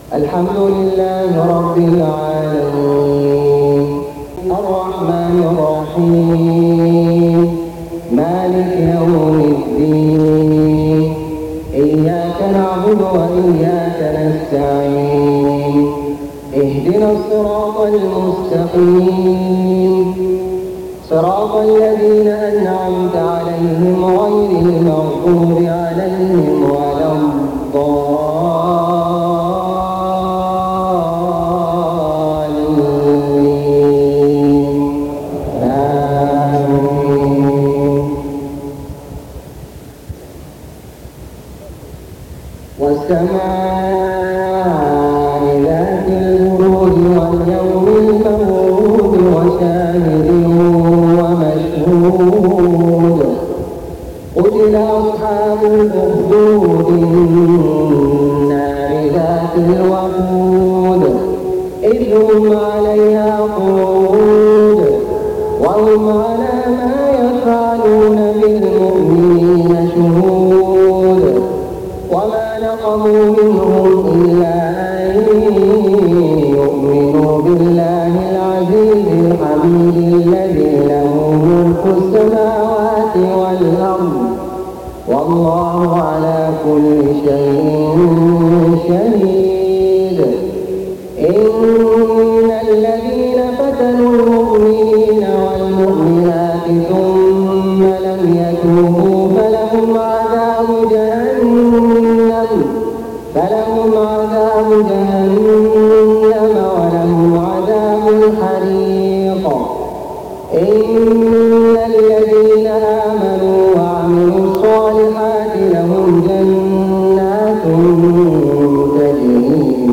صلاة العشاء 18 محرم 1429هـ سورتي البروج و الضحى > 1429 🕋 > الفروض - تلاوات الحرمين